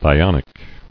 [thi·on·ic]